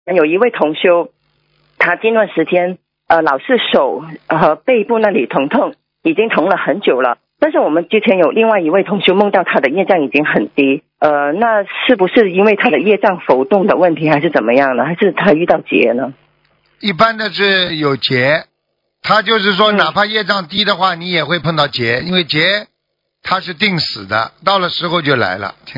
目录：☞ 2019年08月_剪辑电台节目录音_集锦